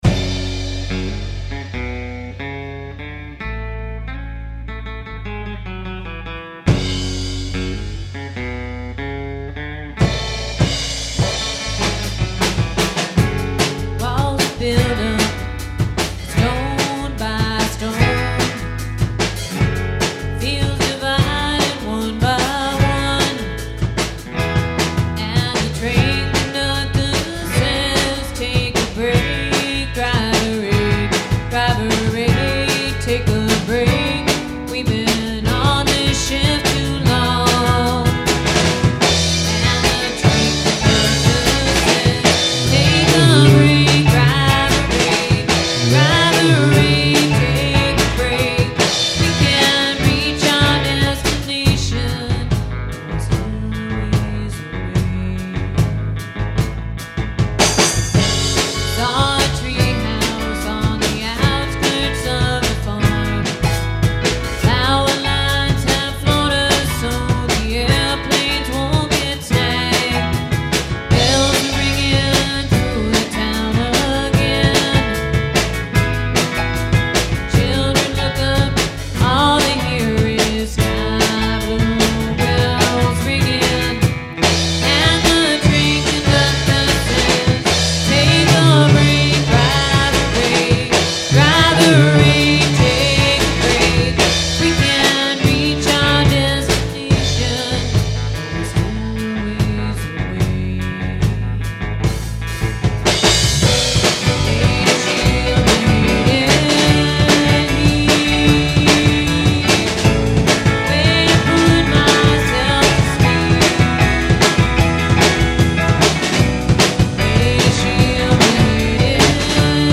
Just Some Demos We Recorded in Our Basement
drums, percussion
keyboards, saxophone, vocals